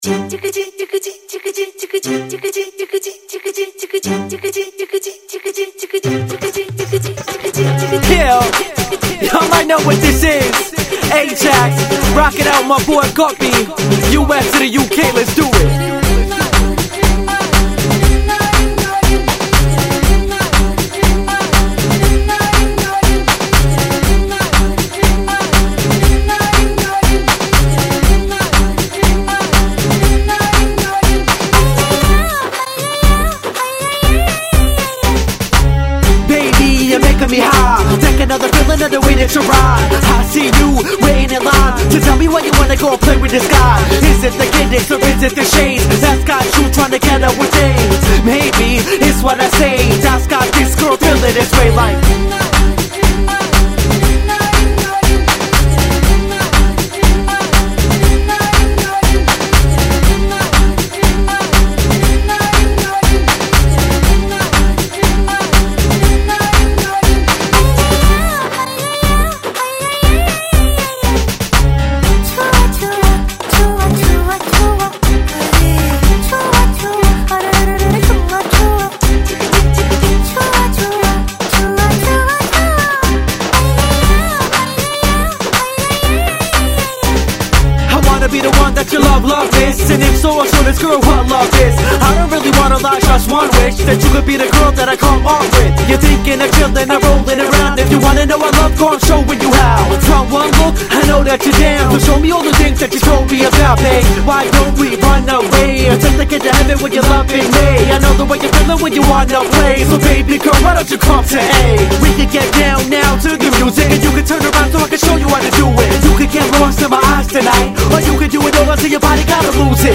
a hip-hop remix